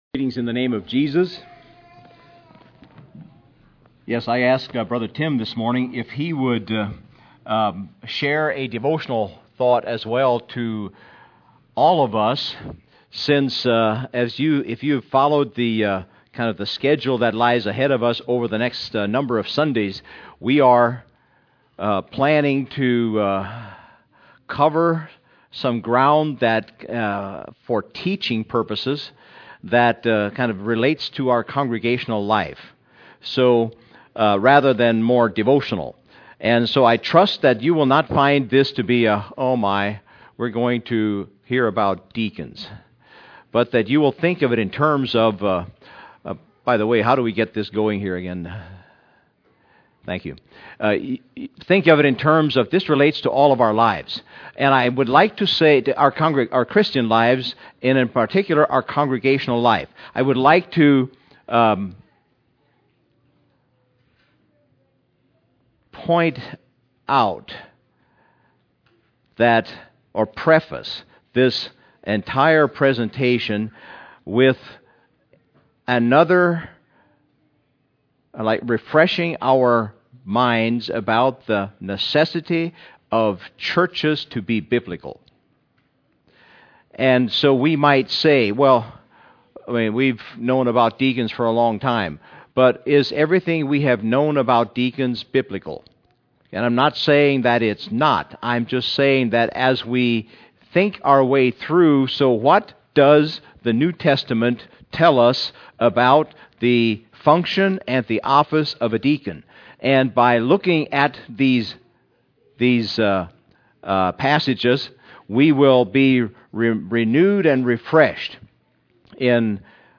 August 2, 2015 – Crosspointe Mennonite Church